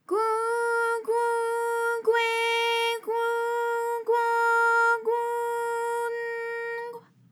ALYS-DB-001-JPN - First Japanese UTAU vocal library of ALYS.
gwu_gwu_gwe_gwu_gwo_gwu_n_gw.wav